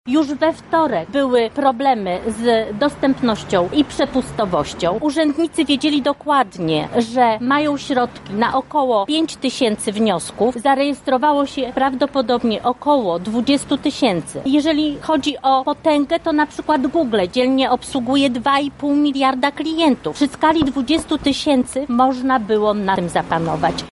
To jest instytucja utrzymywana za publiczne pieniądze i w swoich działaniach powinna osiągać założone cele– mówi radna Sejmiku Województwa Lubelskiego Bożena Lisowska: